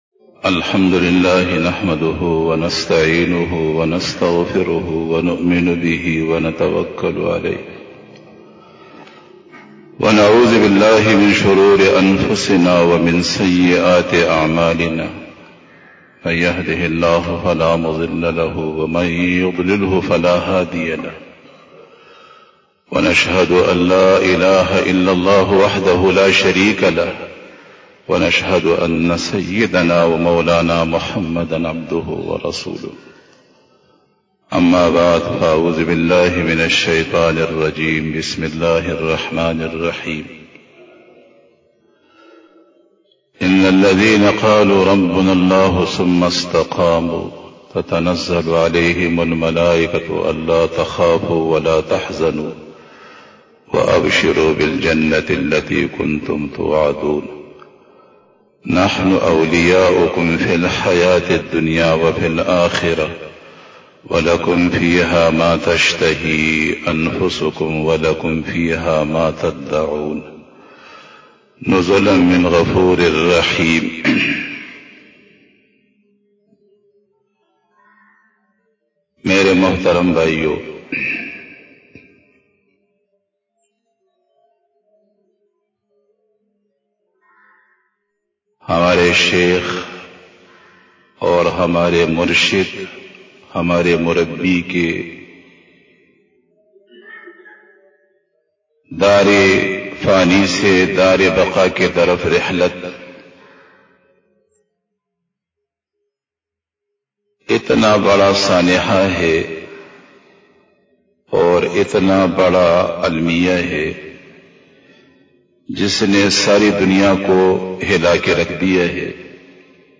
44 BAYAN E JUMA TUL MUBARAK 11 December 2020 (25 Rabi us Sani 1442H)
Khitab-e-Jummah 2020